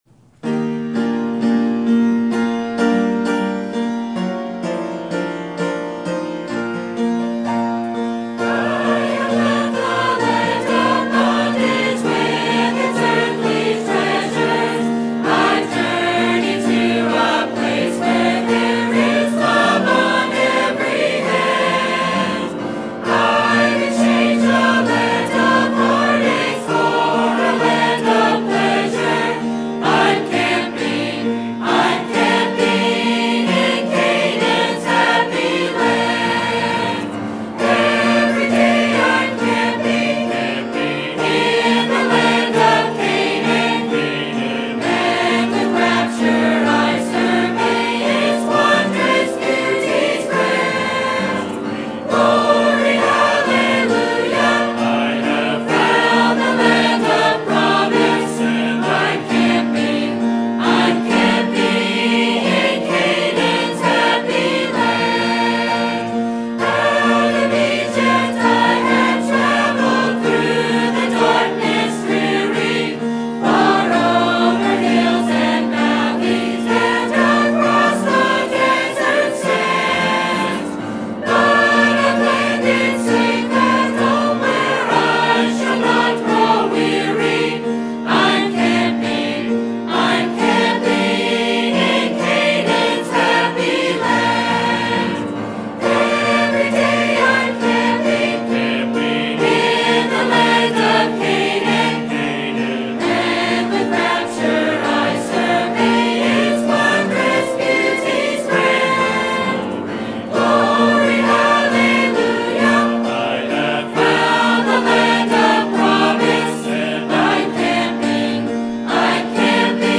Sermon Topic: General Sermon Type: Service Sermon Audio: Sermon download: Download (37.98 MB) Sermon Tags: Deuteronomy Baptist History Principle